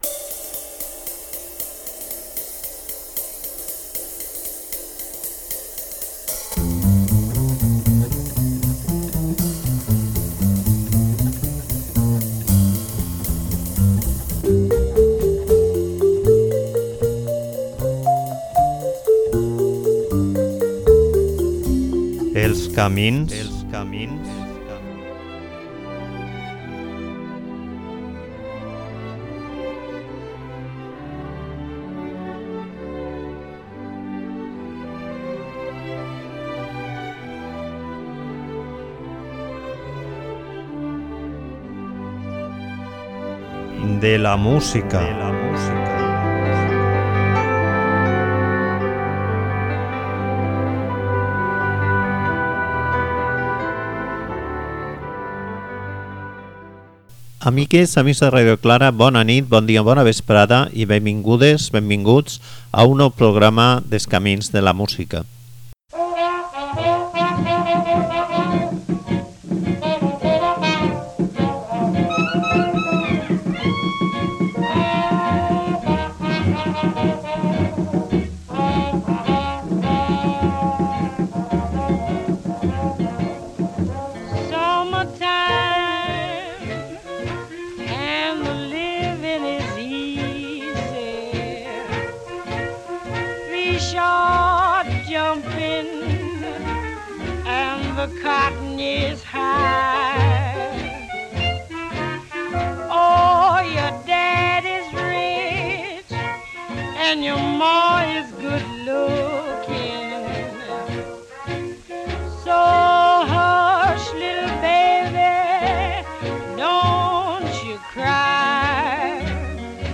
Són peces de moda, amb un temps mitjà apte per ballar, què era el que la gent demanava a les gravadores.